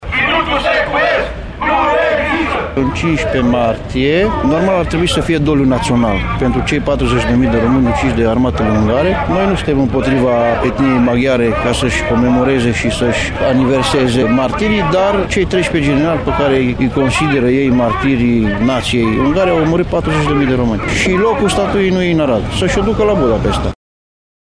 Un reportaj